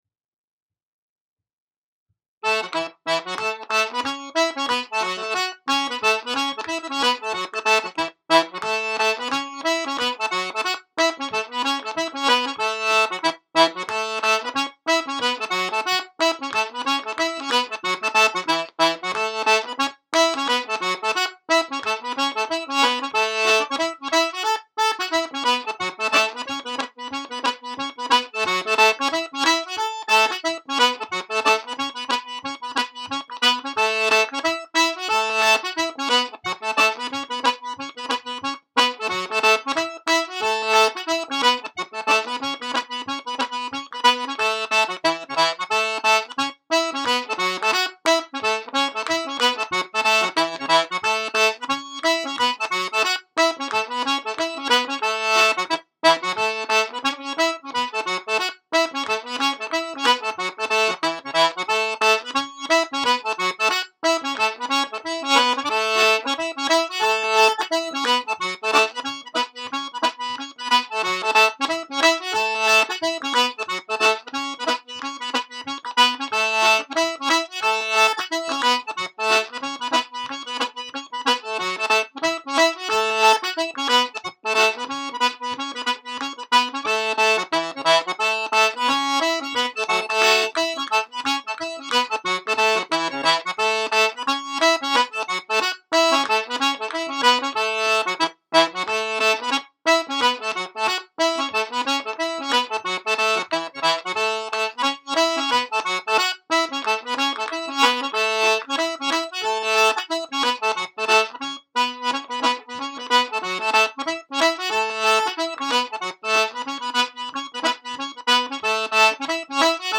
Reels | Cuz Teahan’s or Road to Glountane (90 bpm)